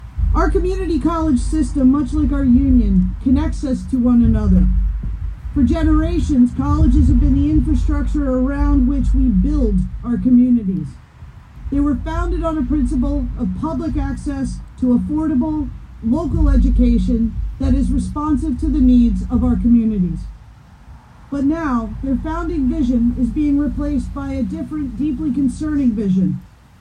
Hundreds of OPSEU members from the Quinte area’s post secondary institution, St Lawrence College in Kingston, and Algonquin College in Ottawa were also on hand.